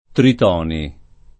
[ trit 0 ni ]